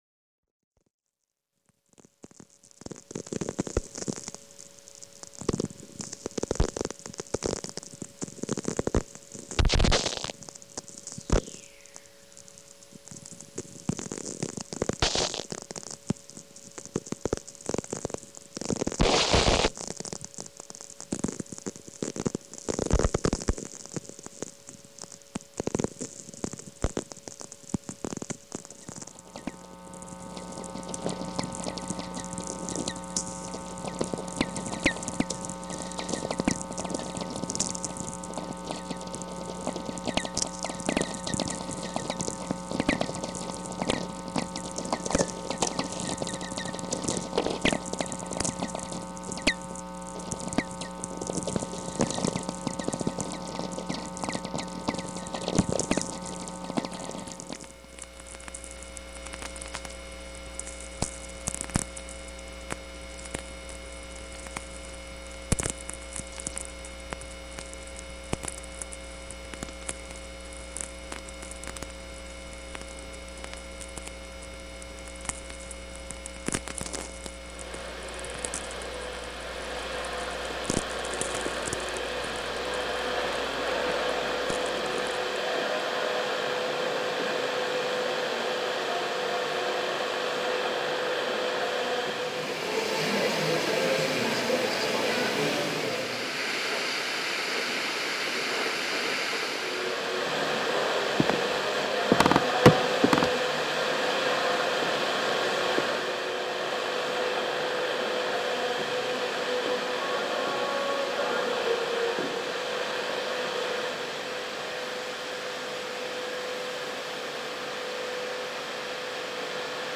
Parts of the conversation have been mixed with field recordings and found footage from the Signal Identification Wiki.
"Transmission Ecologies" explores the turbulent world of radio signals which propagate around us. Each show features a guest sound artist who broadcasts their radio experiments using EMFs, interference patterns from devices, HAM, RF field recordings, satellite signals, space astronomy research, etc. to formulate their interpretations, compositions, and translations of the invisible and unheard layer of telecommunication technologies.